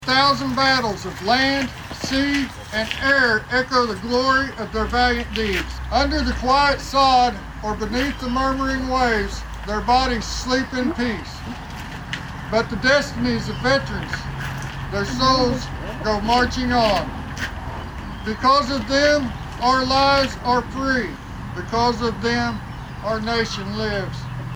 Veterans Remembered at Ceremony in Ramona
Several gathered at the Ramona Cemetery on this Memorial Day Monday to honor those who sacrificed everything.